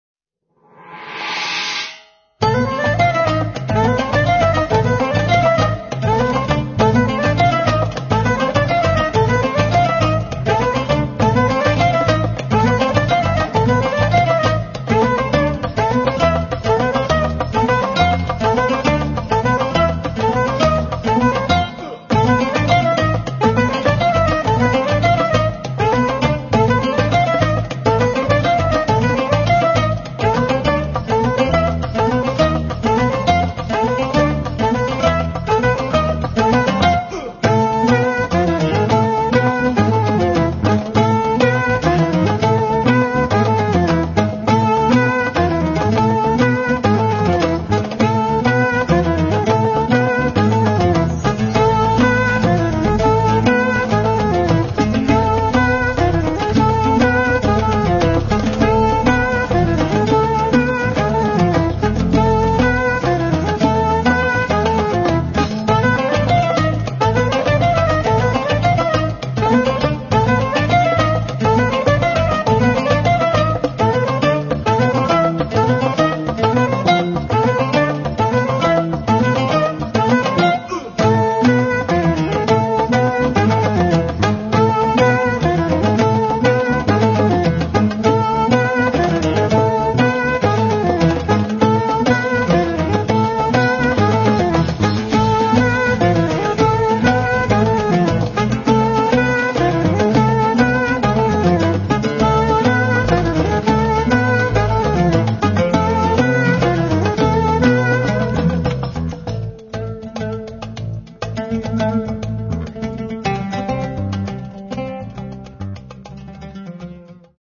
electroacoustic & electric bass, guitar
saxophones, bass clarinet, wooden flute
sitar, oud, guitars
percussions, tanpura
Registrato a Bari